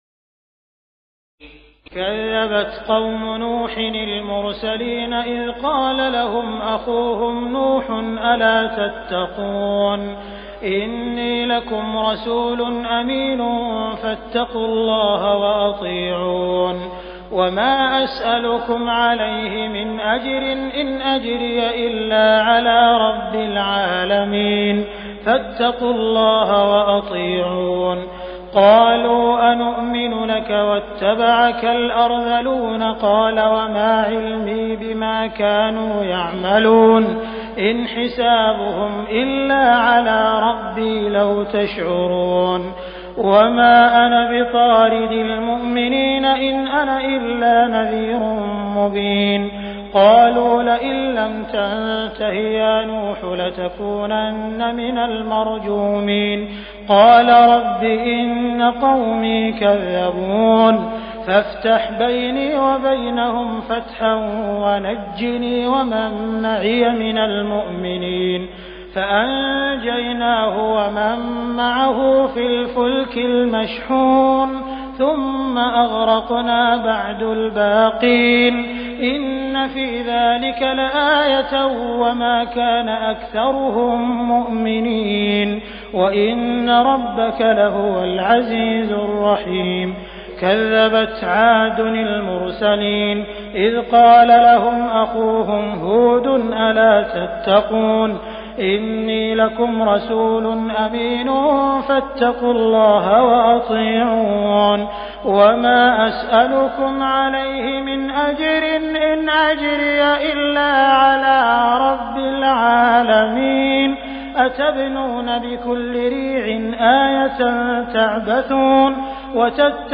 تراويح الليلة الثامنة عشر رمضان 1418هـ من سورتي الشعراء (105-227) والنمل (1-53) Taraweeh 18 st night Ramadan 1418H from Surah Ash-Shu'araa and An-Naml > تراويح الحرم المكي عام 1418 🕋 > التراويح - تلاوات الحرمين